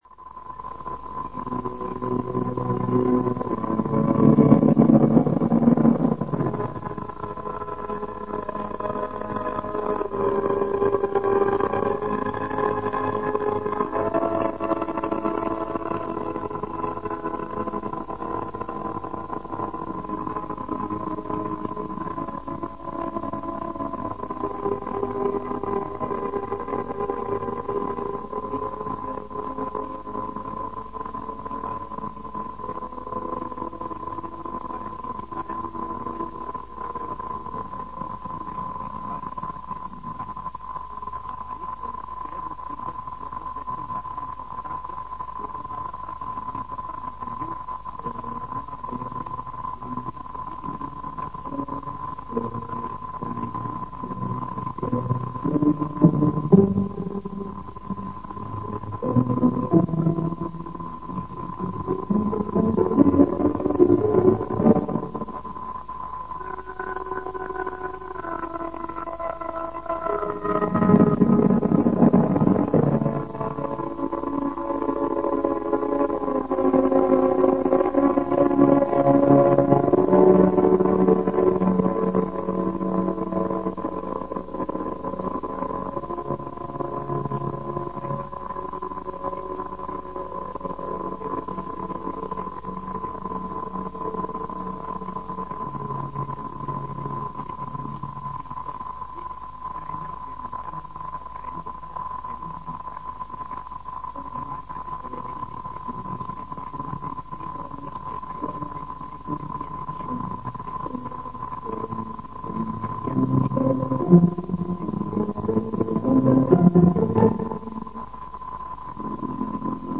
Zene
rossz hangminőség